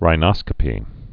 (rī-nŏskə-pē)